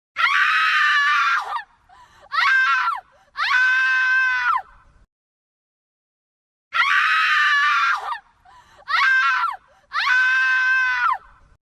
Categoria Efeitos Sonoros